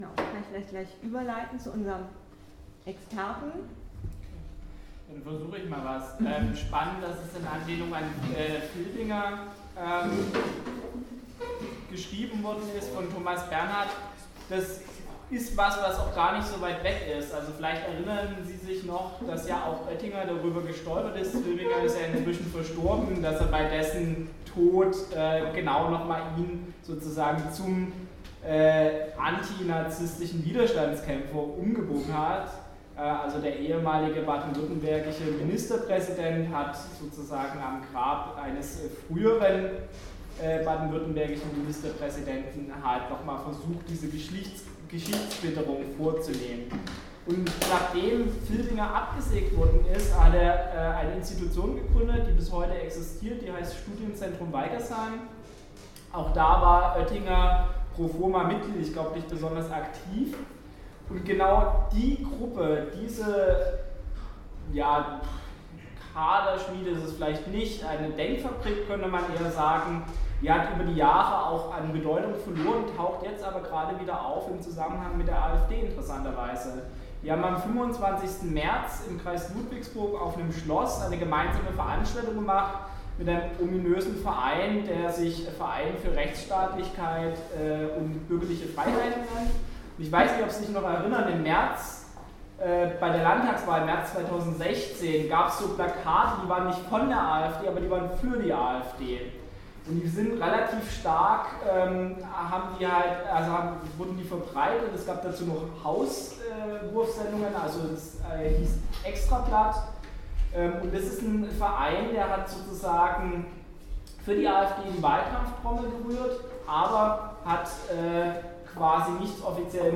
Publikumsgespräch zum Theaterstück Vor dem Ruhestand